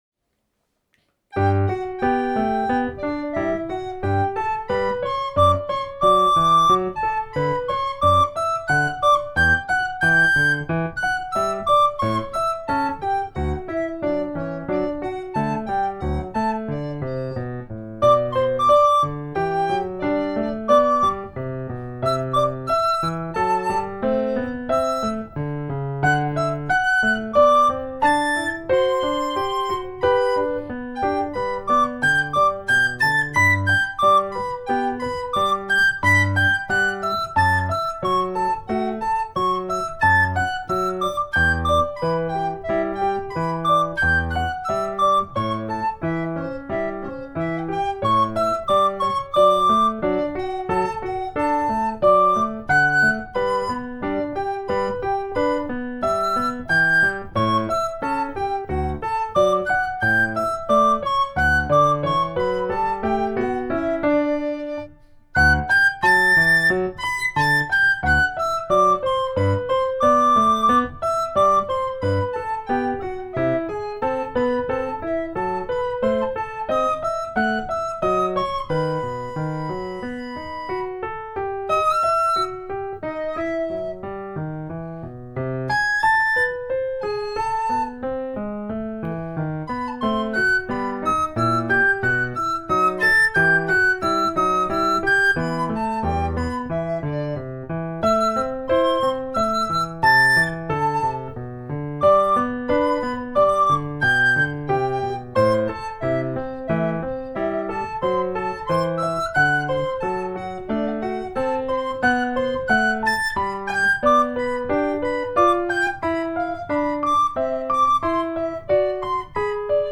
piano
flauta